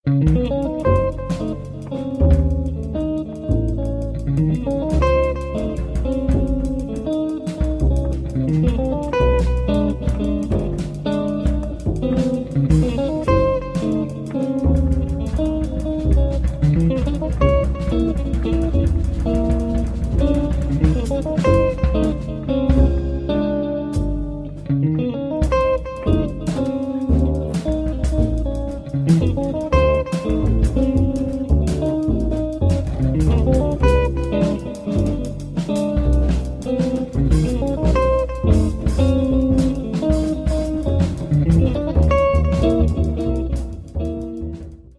Contemporary Jazz Guitar meets Pitch Class Set Improvisation
Guitar
Bass
Drums